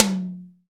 TOM ATTAK 06.wav